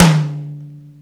• Big Room Tome Drum Sample D Key 35.wav
Royality free tom drum single hit tuned to the D note. Loudest frequency: 1450Hz
big-room-tome-drum-sample-d-key-35-TKw.wav